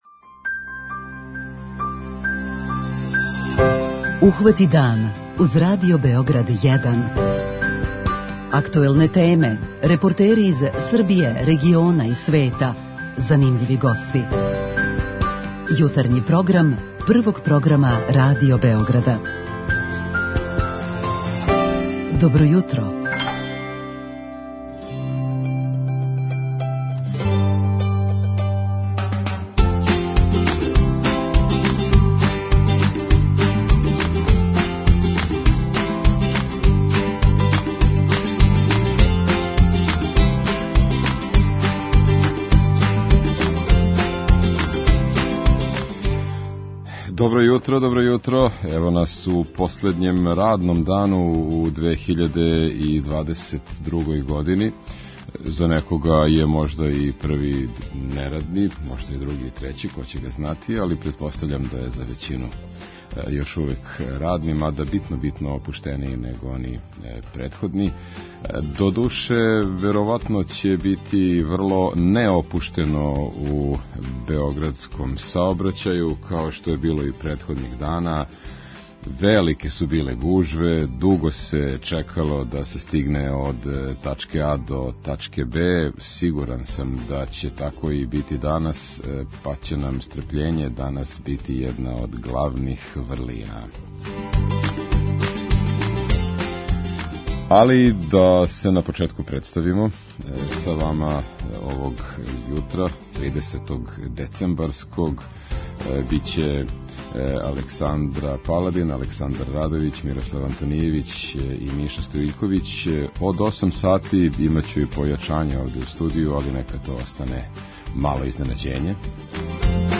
У првом делу емисије укључиваћемо наше дописнике из разних делова Србије и од њих чути како ће у њиховим градовима и околини бити дочекана 2023. година.
У другом делу - од 8 до 10 сати - укључиваћемо слушаоце у програм и делити пригодне поклоне које су припремили наши спонзори. Наравно, уз то иде и пригодна претпразнична музика.